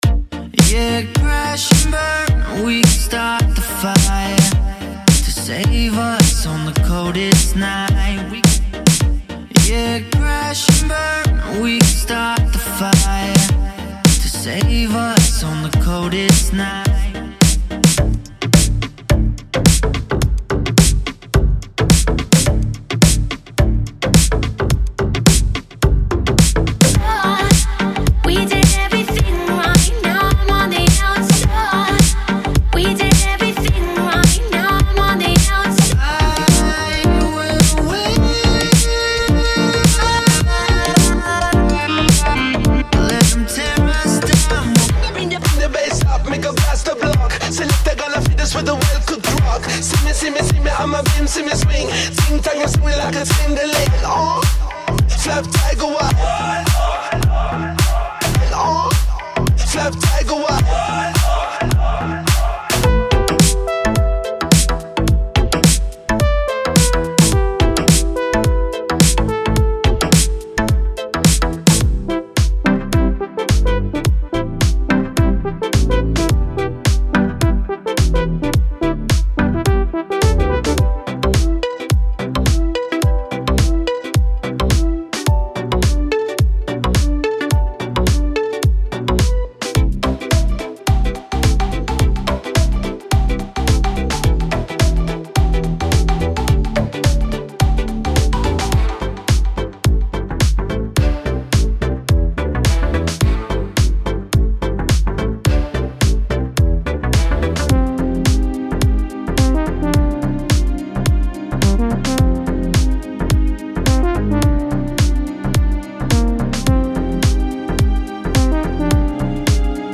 Как вам звучание,данного микса ?Поппури.